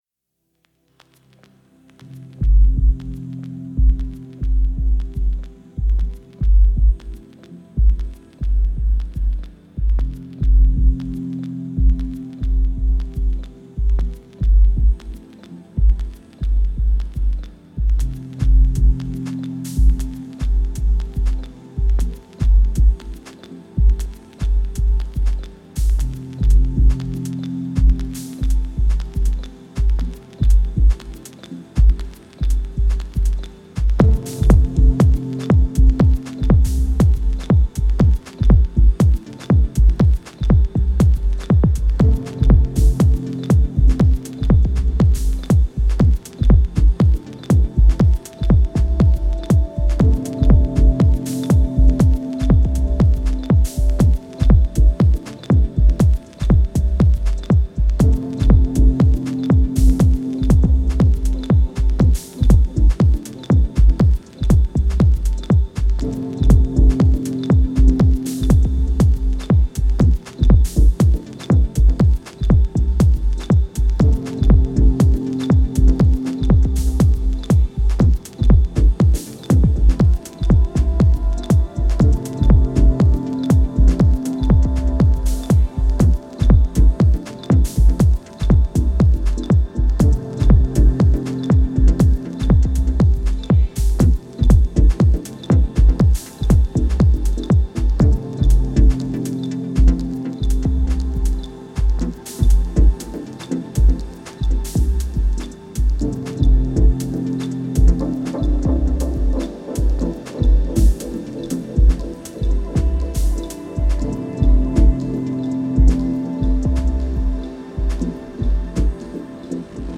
Genre: Deep Techno/Dub Techno.